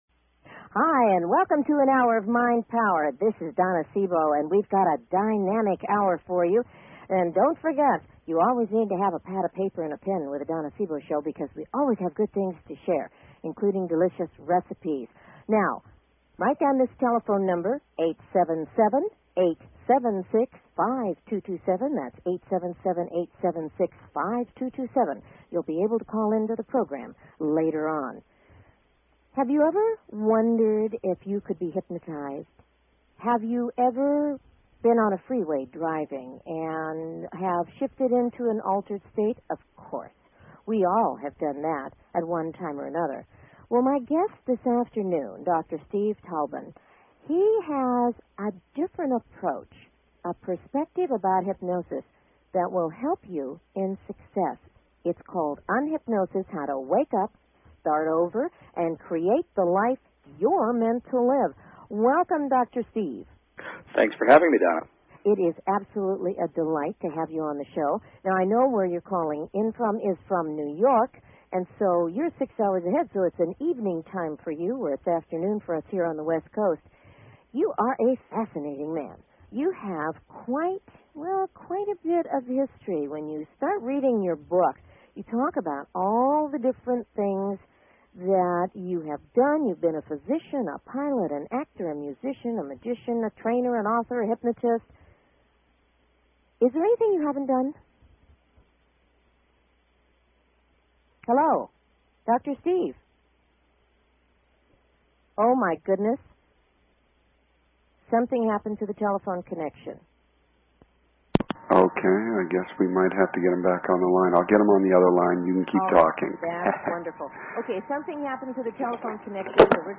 Her interviews embody a golden voice that shines with passion, purpose, sincerity and humor.
Talk Show
Tune in for an "Hour of Mind Power". Callers are welcome to call in for a live on air psychic reading during the second half hour of each show.